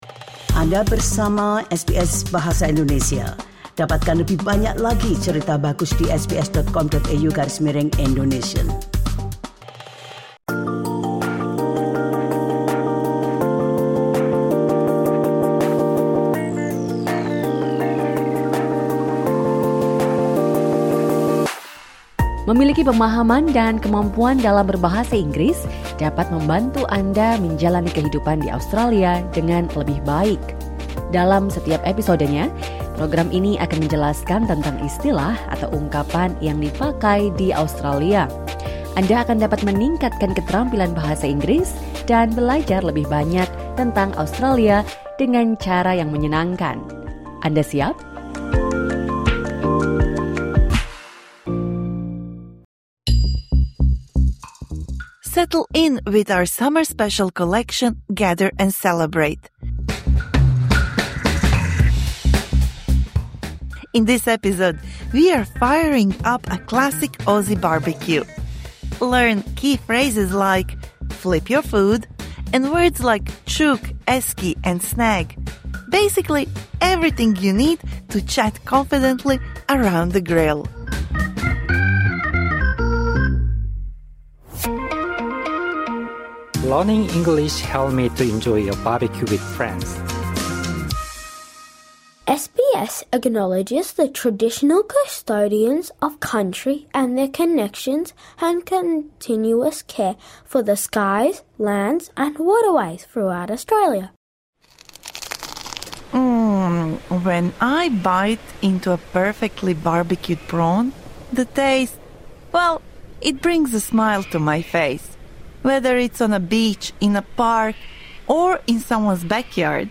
This lesson is suitable for intermediate-level learners.